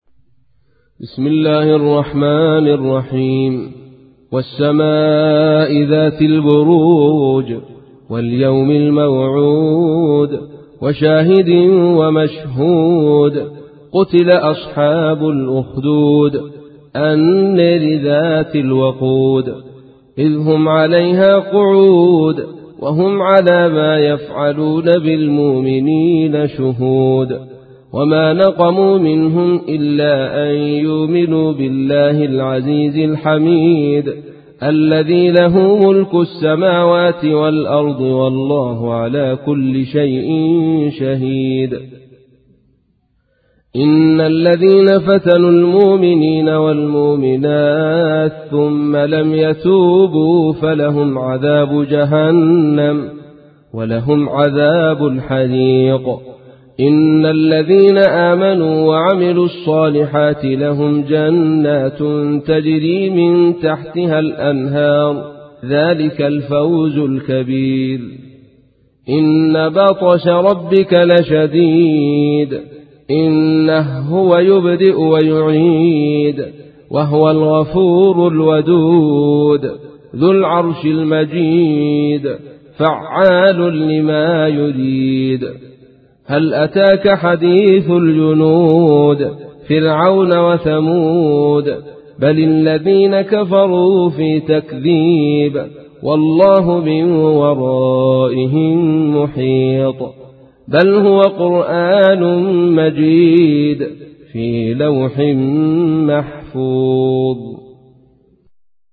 تحميل : 85. سورة البروج / القارئ عبد الرشيد صوفي / القرآن الكريم / موقع يا حسين